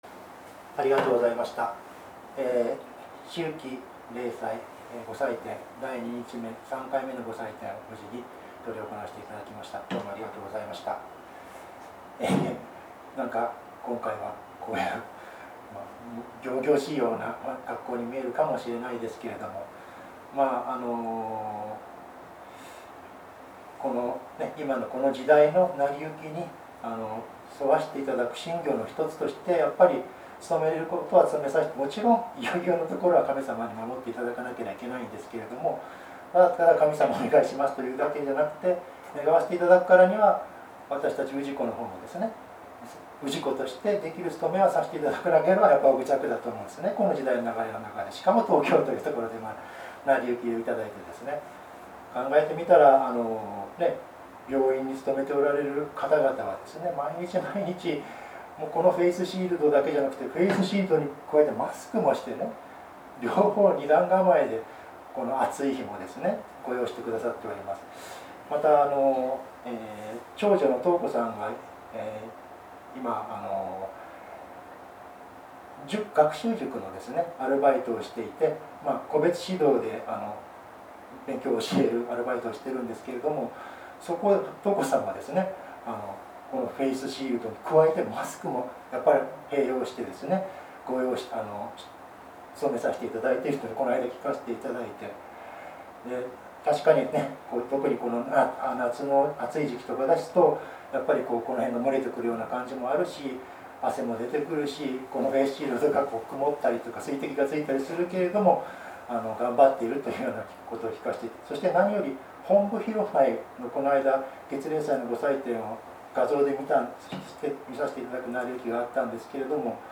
秋季霊祭教話